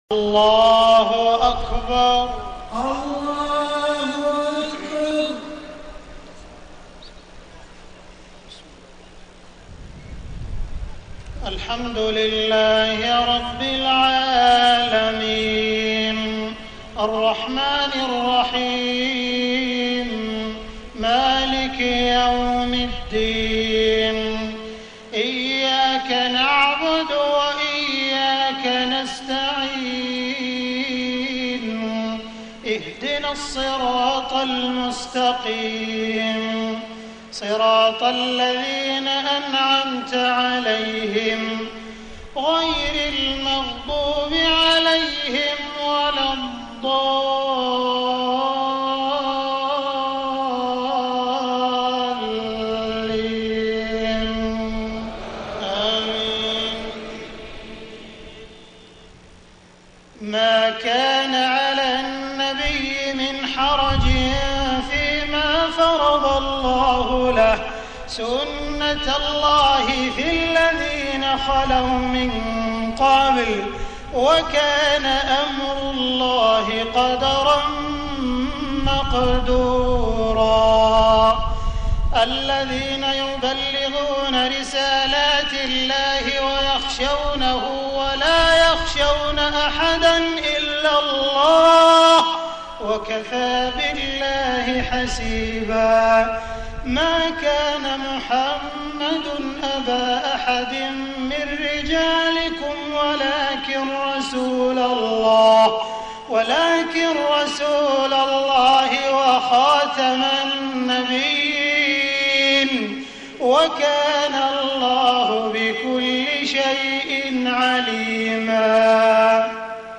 صلاة المغرب 27 صفر 1431هـ من سورة الأحزاب 38-48 > 1431 🕋 > الفروض - تلاوات الحرمين